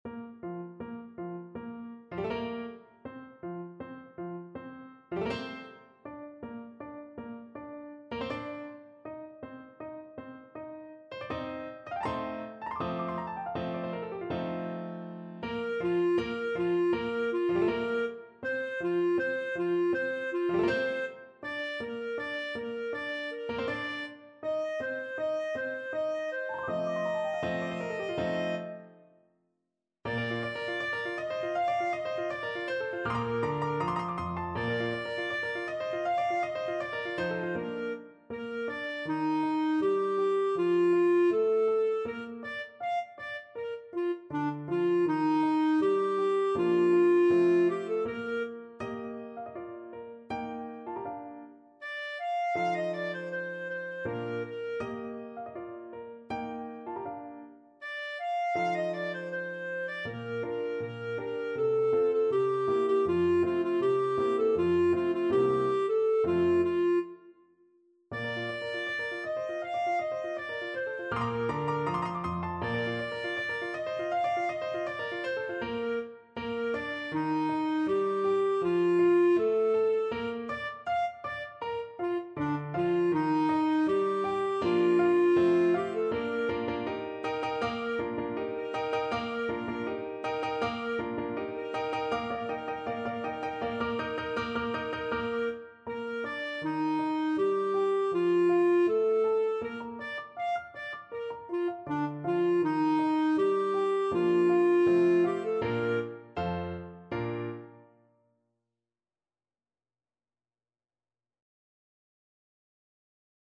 Clarinet version
Molto allegro =160
4/4 (View more 4/4 Music)
D5-F6
Classical (View more Classical Clarinet Music)